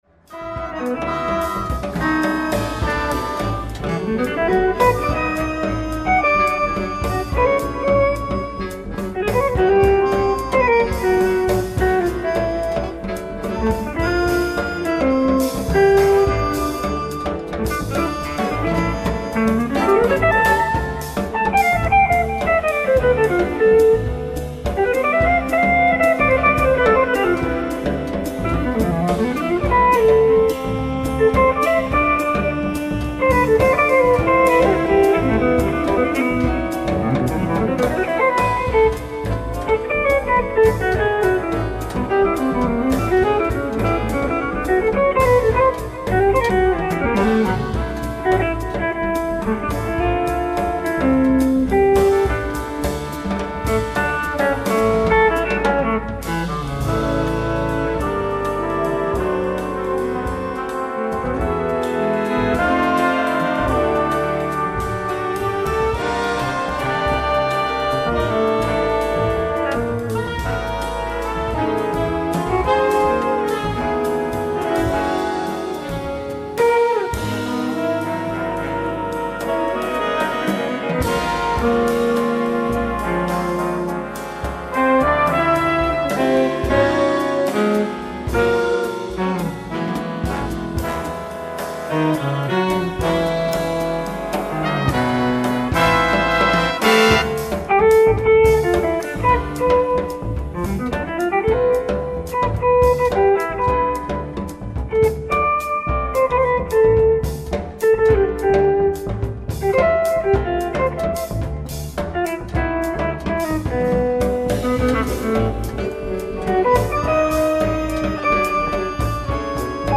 ライブ・アット・フランクフルト、ドイツ 06/12/2015
※試聴用に実際より音質を落としています。